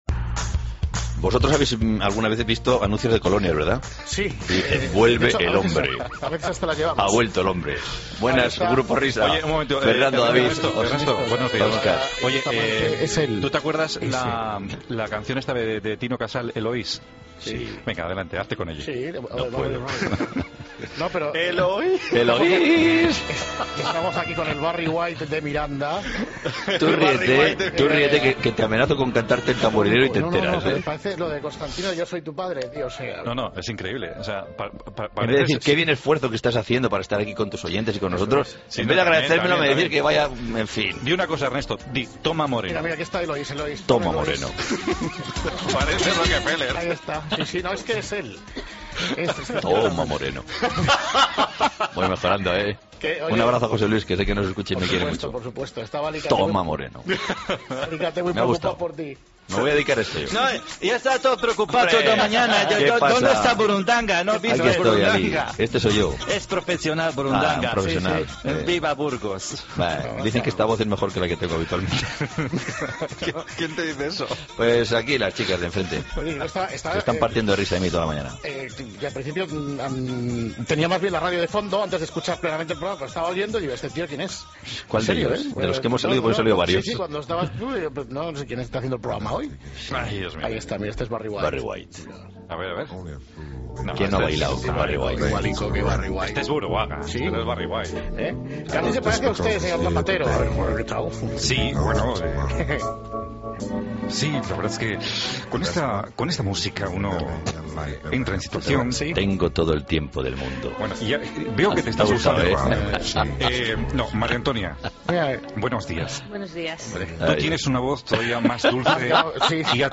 AUDIO: Buruaga como Barry White y la frase del día de Artur Mas